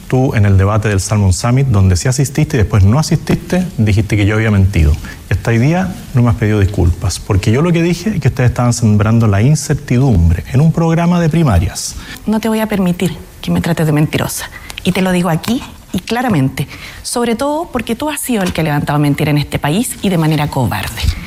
Los dardos cruzados que dejó primer debate presidencial televisivo